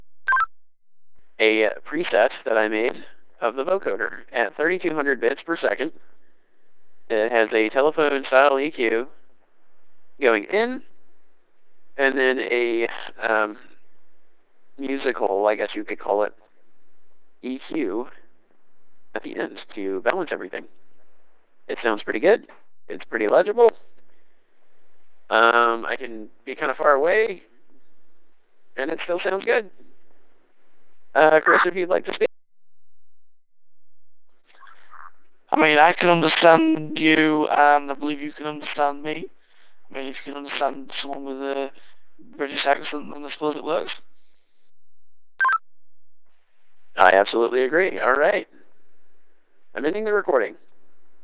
The Vocoder voice effect simulates a modern P25-style radio compression using an AMBE-like encoding approach, commonly heard in proprietary digital radio systems.
These voice effects combines the vocoder with pre and post-effect EQ customizations for the best possible clarity.
#1 Vocoder
vocoder_example_1.mp3